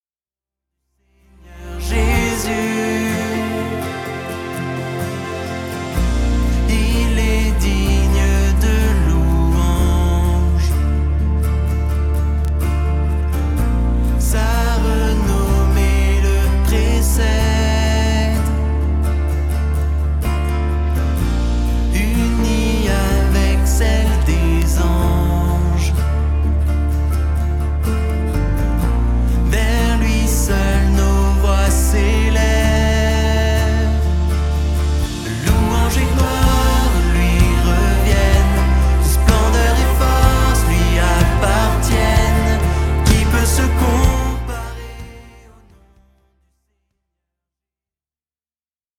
louanges